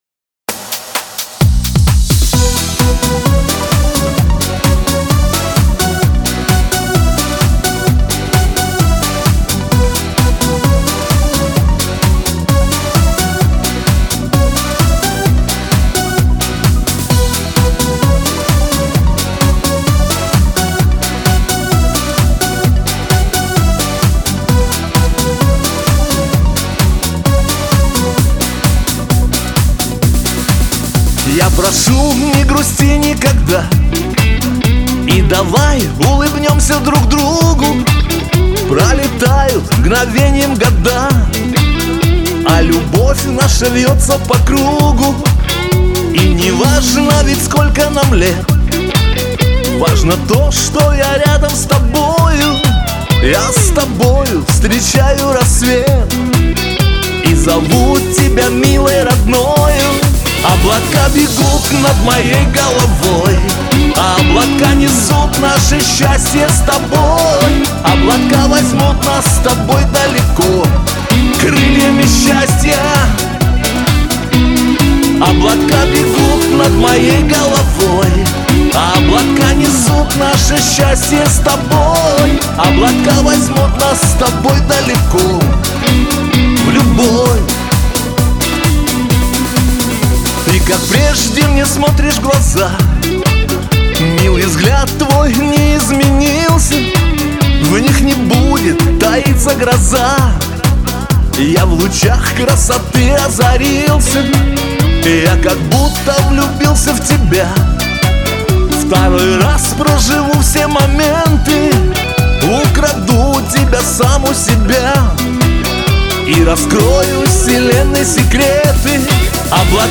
Лирика
Шансон